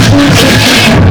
noisecore,